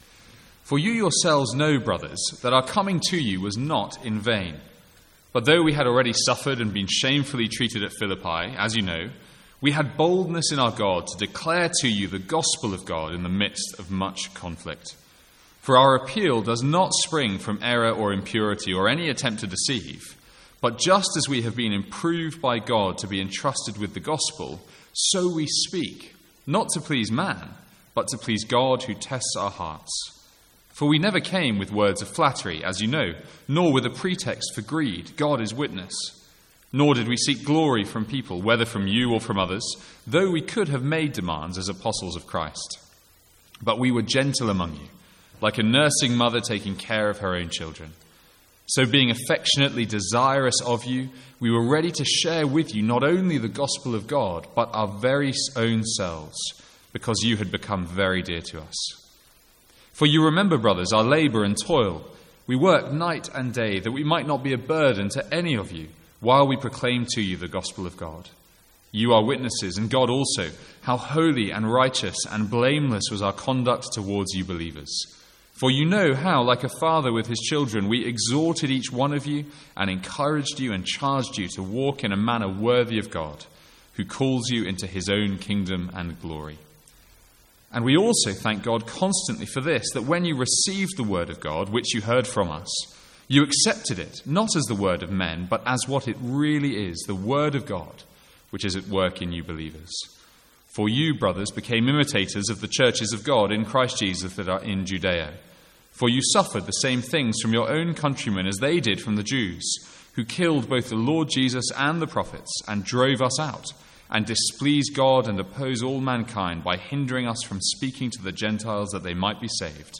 Sermons | St Andrews Free Church
From the Sunday evening series in 1 Thessalonians.